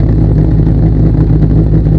scsm_engine2w.wav